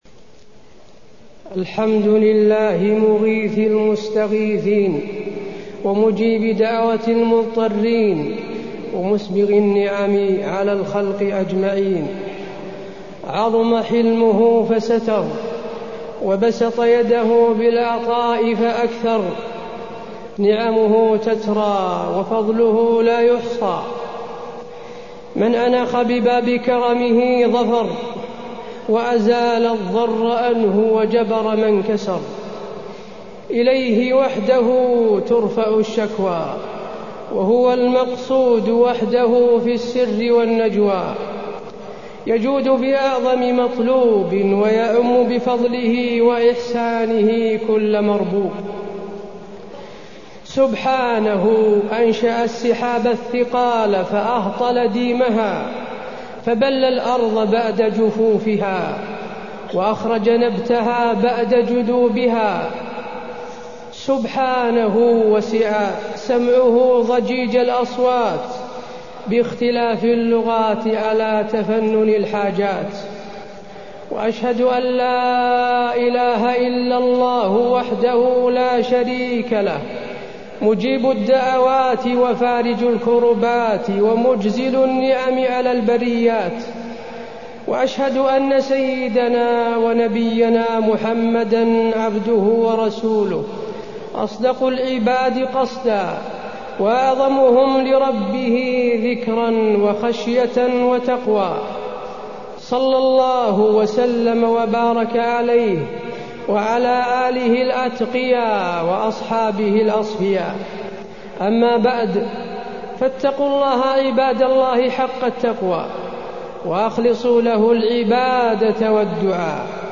خطبة الاستسقاء - المدينة- الشيخ حسين آل الشيخ
المكان: المسجد النبوي